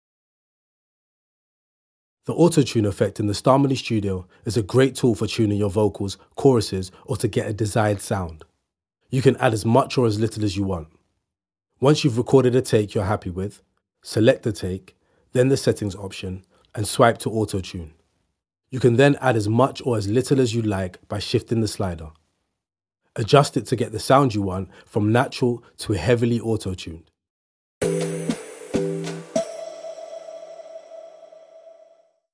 You hear this vocal Mp3 Sound Effect Autotune™. You hear this vocal effect all. the. time.